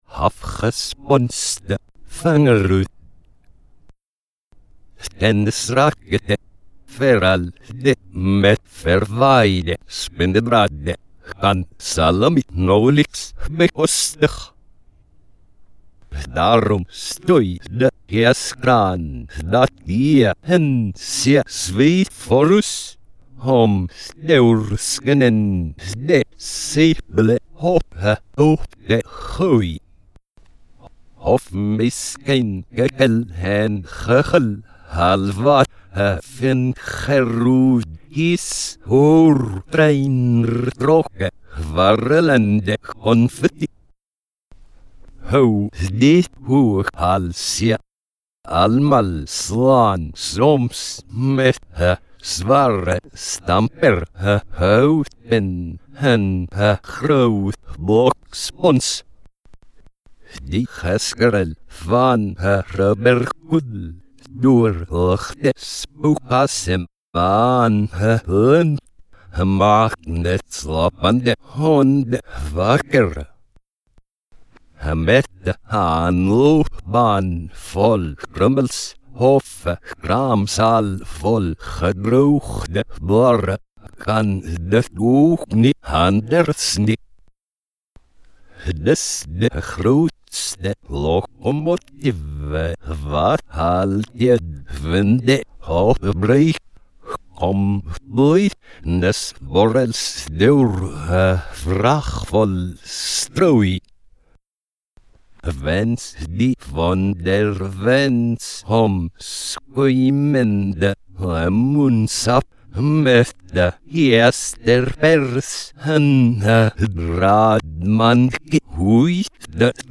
6 4 Afgesponste Vingerhoed Backwards (backwards made normal)